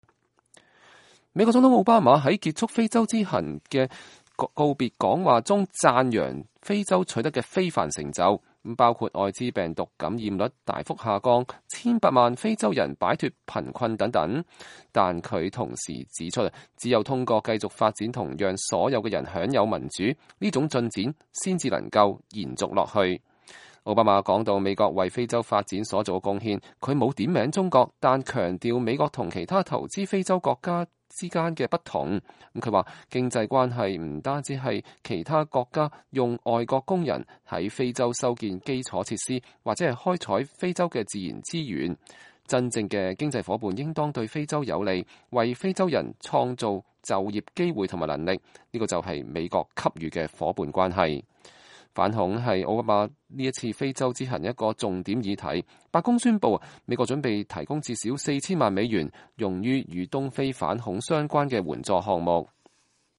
奧巴馬在非盟總部發表講話